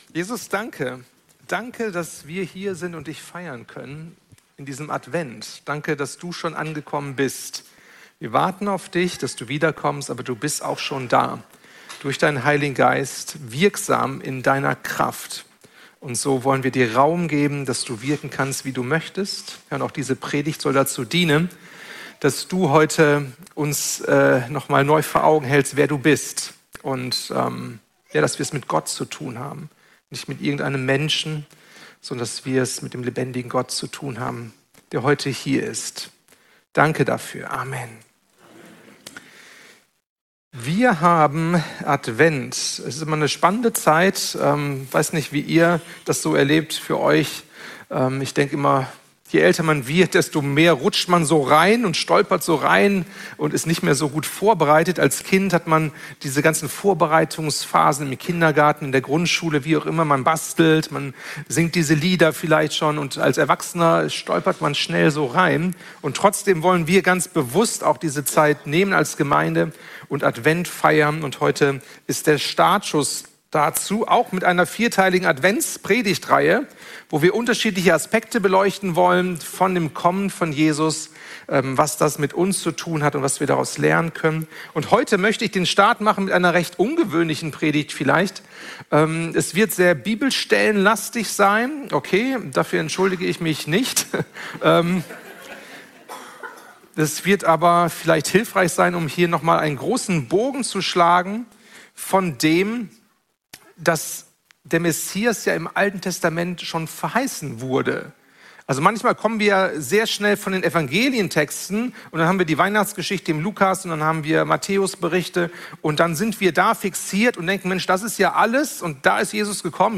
Predigten | Seite 2 | Freie Christengemeinde Bielefeld